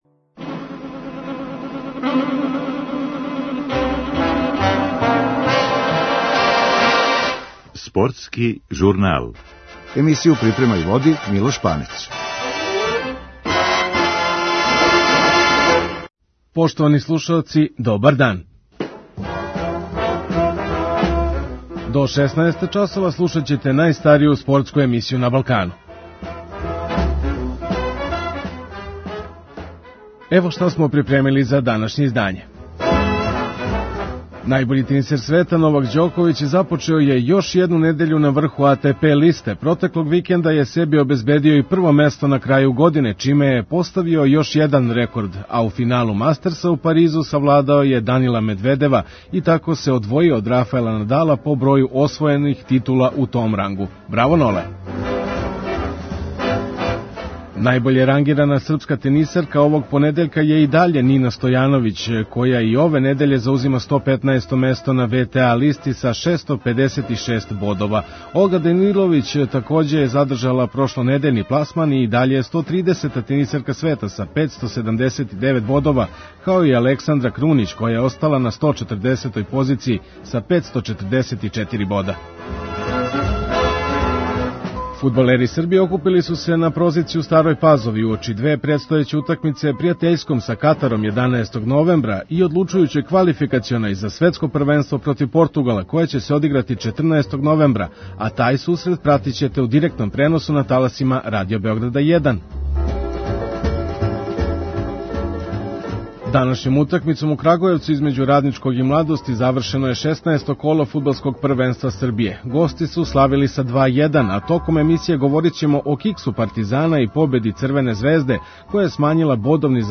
У емисији анализирамо последњи Новаков успех са стручним саговорницима из света тениса.